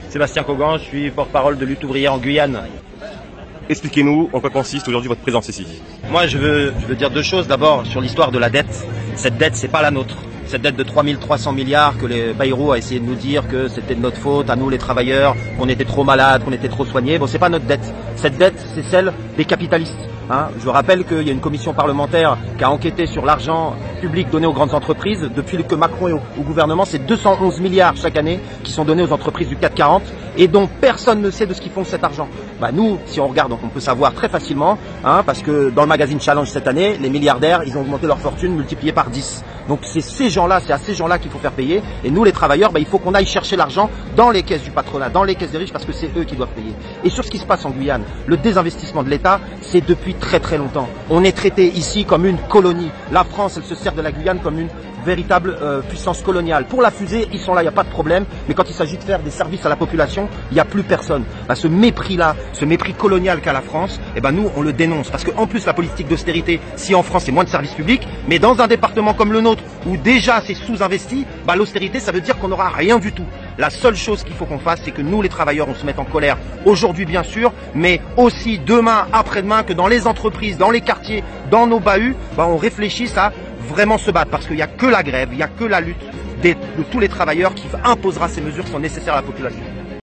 Le Kouroucien : Interview